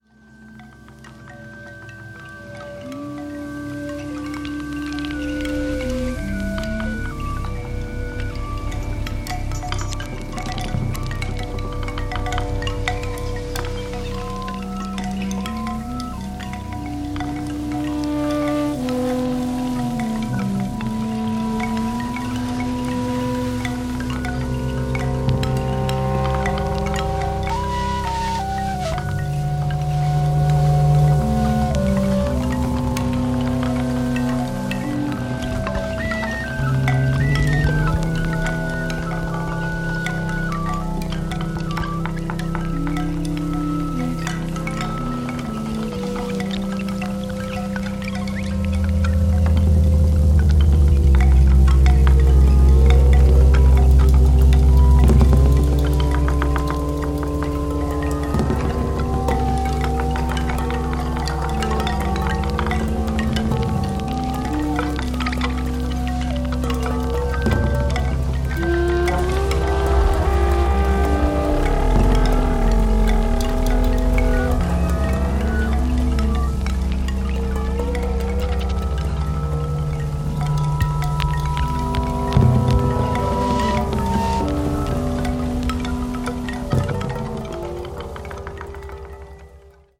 the modular synth
Electronix Ambient